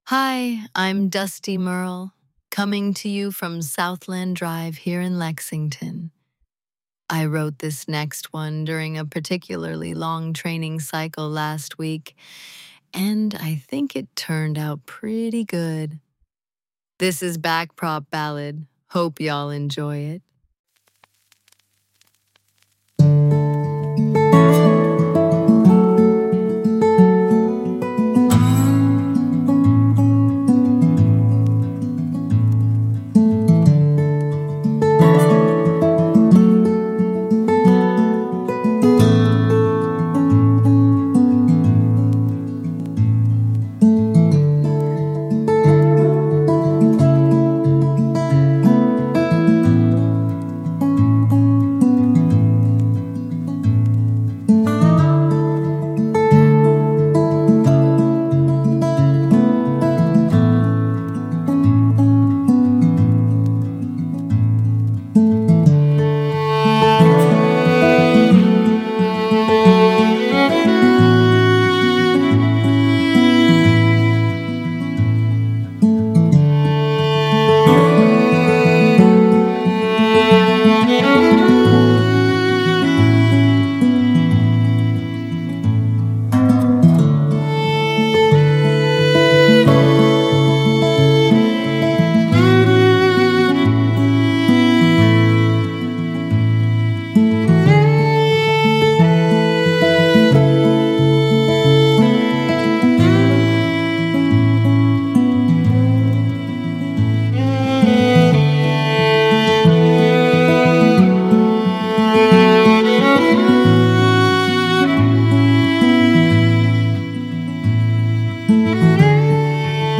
Voice synthesis via ElevenLabs; script via Claude.